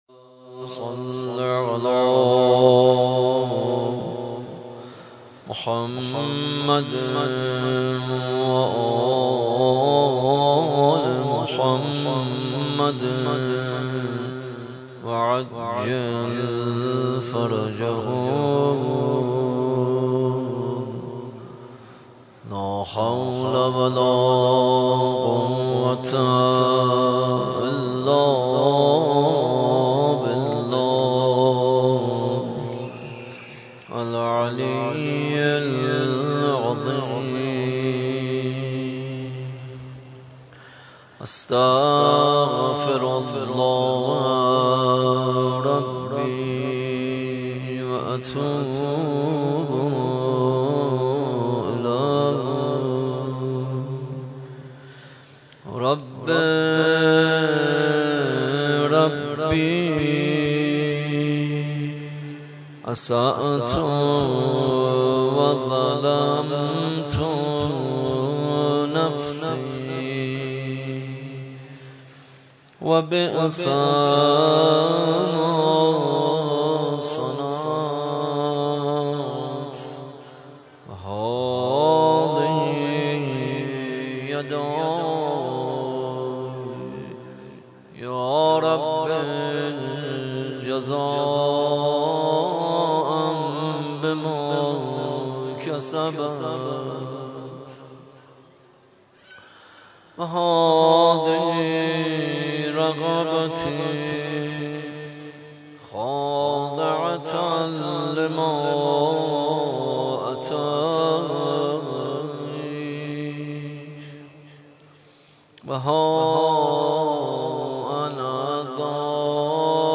شب هجدهم ماه مبارک رمضان دعای ابوحمزه